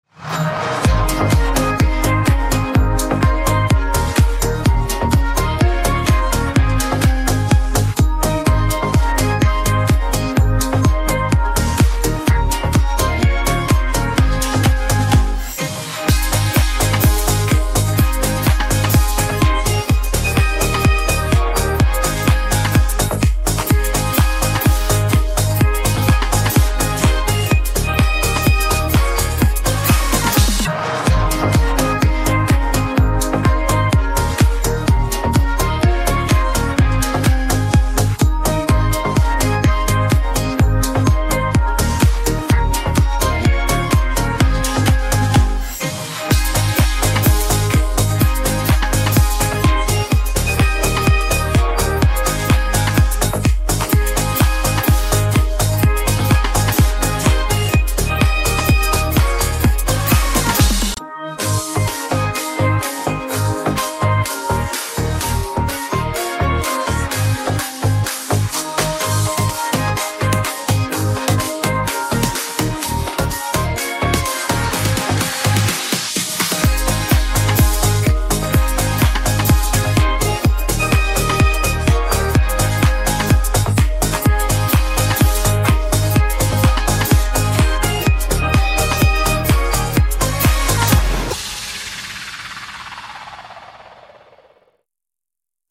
Beim Abspielen könnte es zu spontanen Polonaisen durchs Wohnzimmer kommen!
Pfronstetter-Fasnetslied_Partyversion.mp3